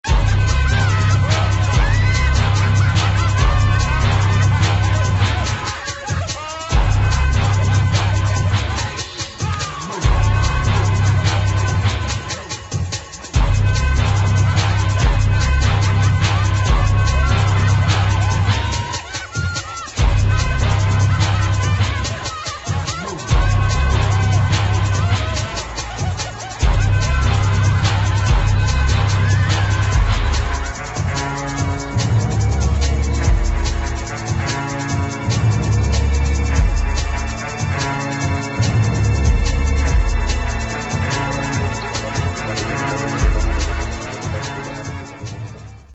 [ BREAKBEAT | DOWNBEAT | DRUM'N'BASS ]